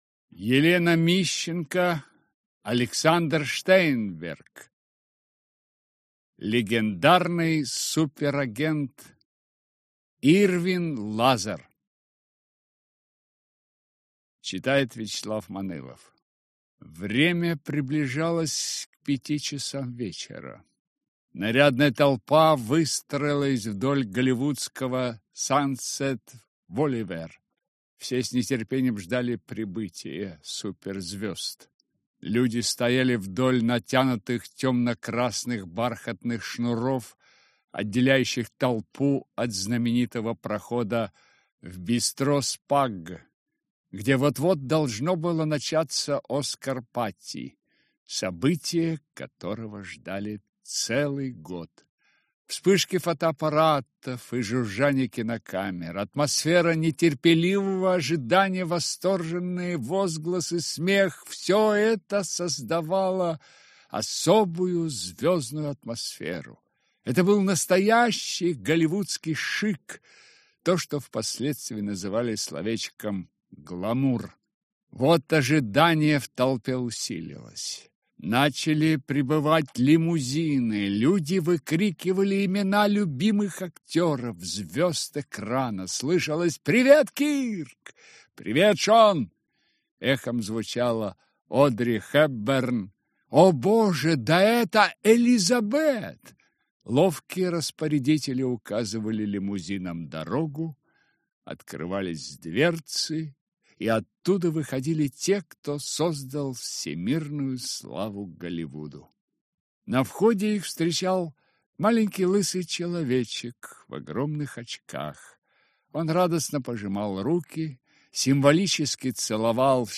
Аудиокнига Легендарный суперагент. Ирвин Лазар | Библиотека аудиокниг